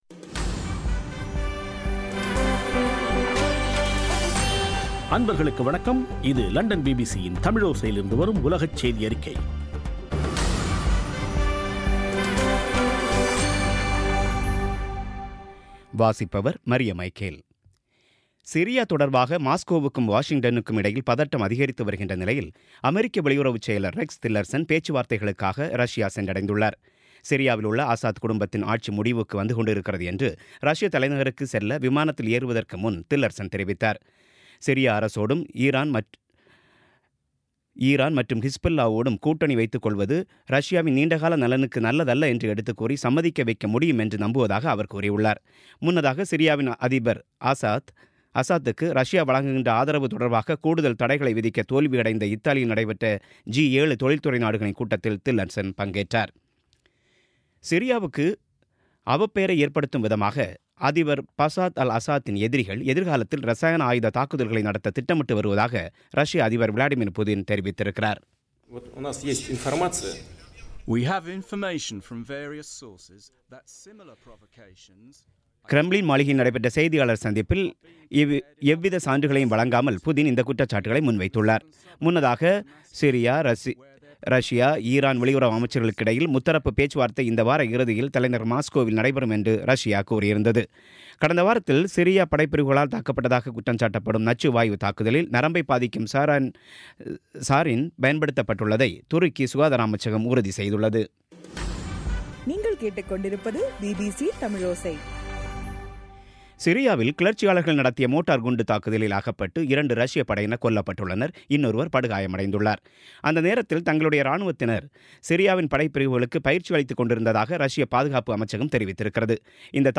பிபிசி தமிழோசை செய்தியறிக்கை (11/04/2017)